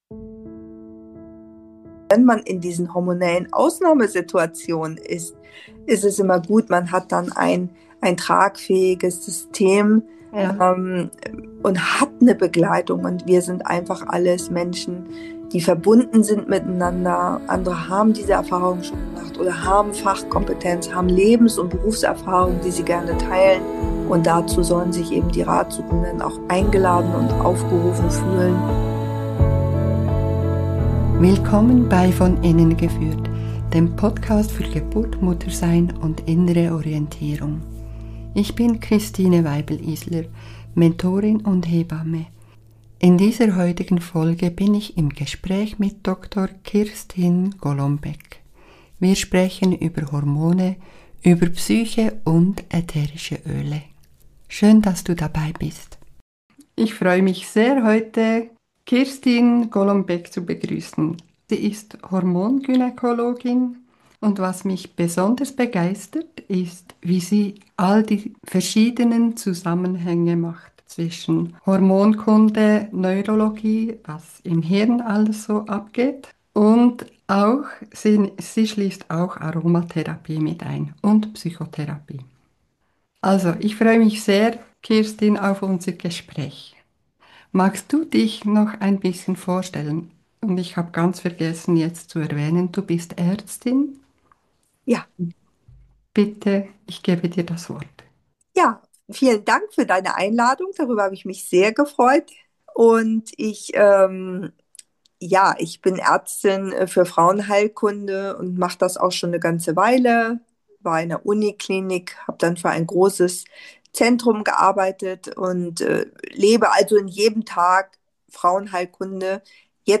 Folge 5: Hormone, Psyche & innere Balance – im Gespräch